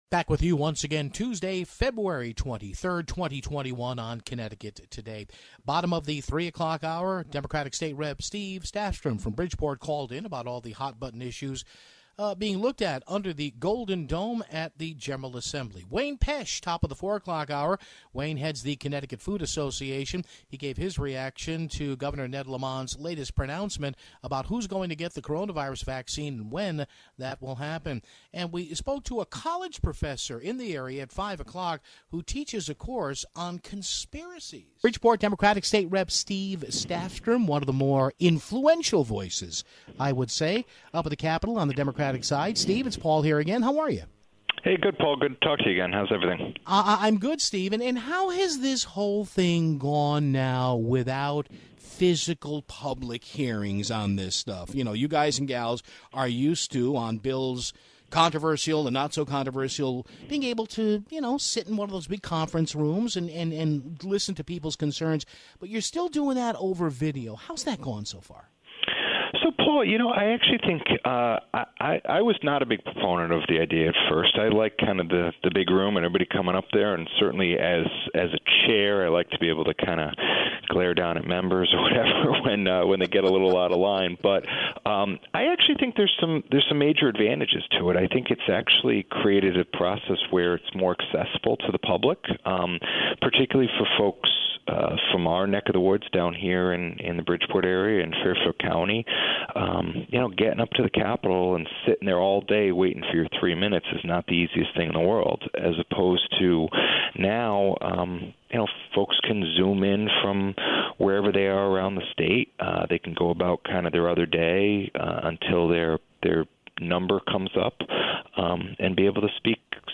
Bridgeport Democratic State Representative Steve Stafstrom called in to talk about all the issues being discussed at the Capitol in Hartford (0:34).